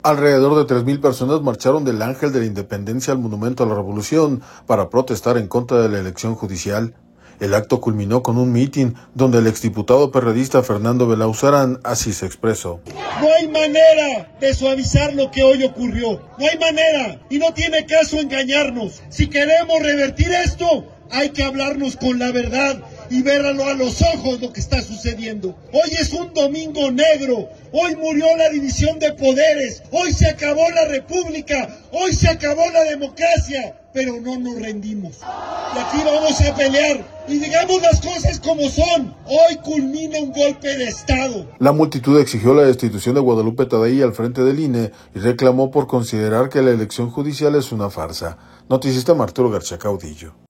audio Alrededor de tres mil personas marcharon del Ángel de la Independencia al monumento a la Revolución para protestar en contra de la elección judicial. El acto culminó con un mítin, donde el ex diputado perredista Fernando Belaunzarán así se expresó.